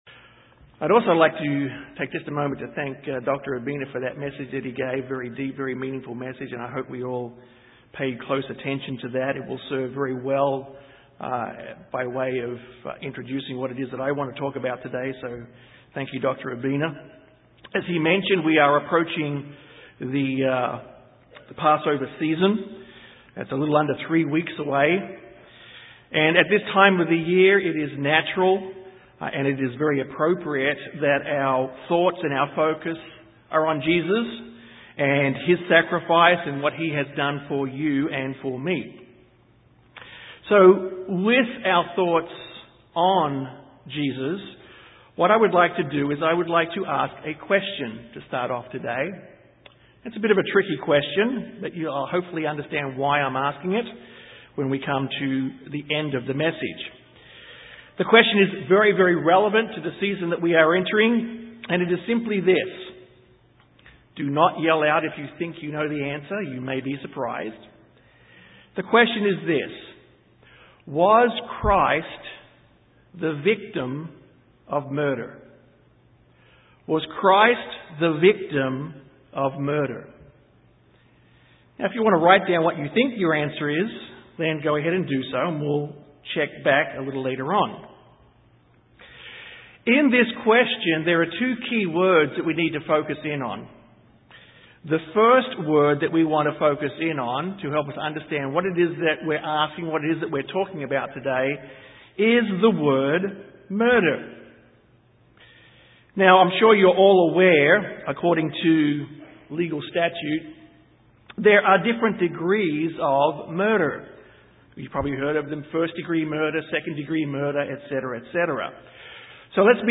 Given in San Antonio, TX